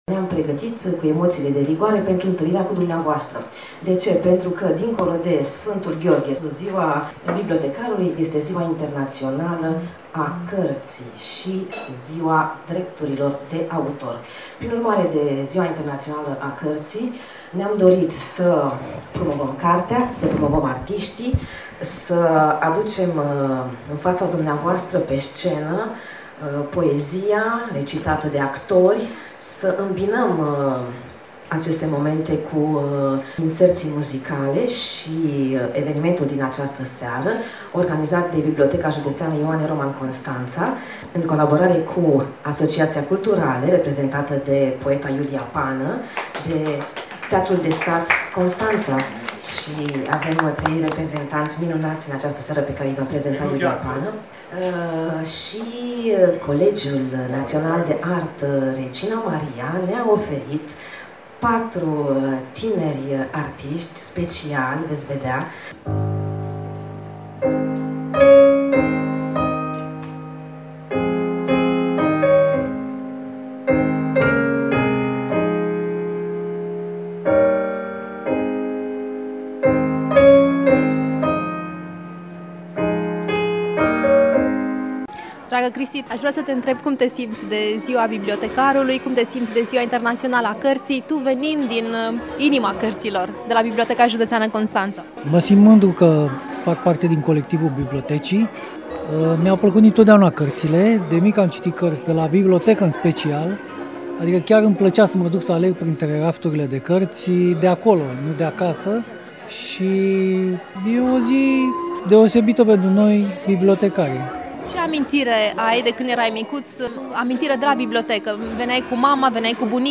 AUDIO | Poezie, cântec și povești spuse de oameni frumoși, la un eveniment dedicat Zilei Bibliotecarilor - Știri Constanța - Radio Constanța - Știri Tulcea